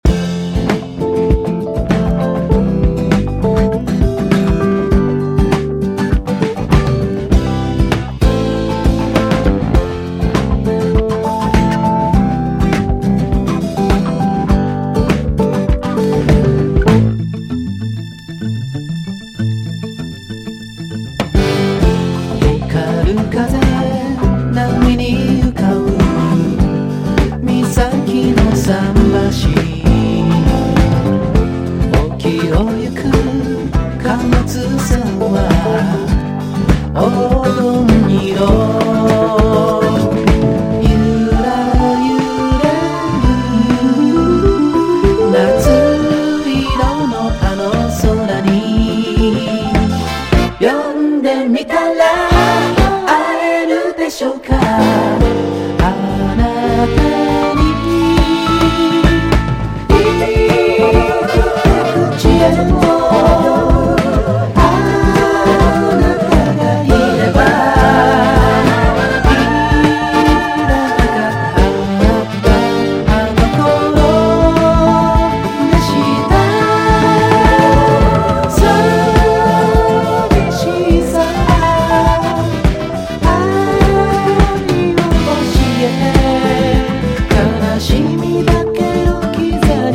コケティッシュなトロピカル・シティ・ポップ！